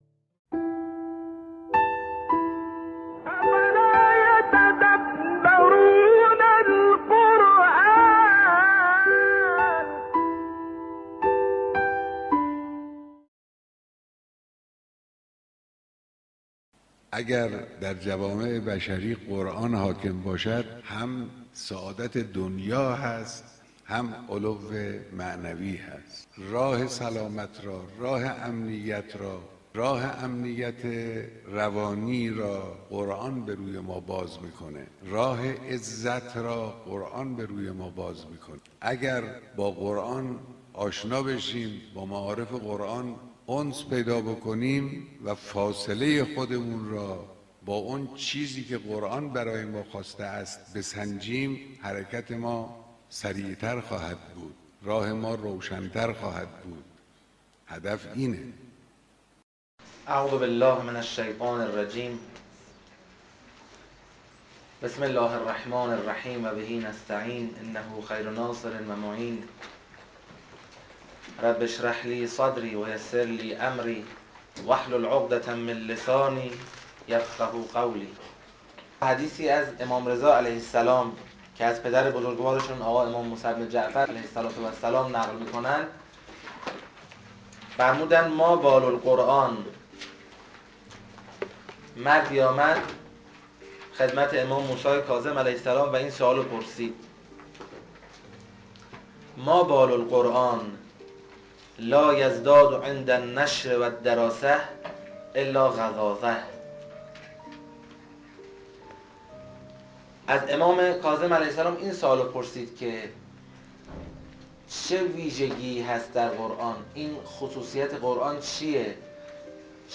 این مجموعه توسط واحد رسانه موسسه تدبر از کلاس ها و سخنرانی های ایشان تهیه شده است.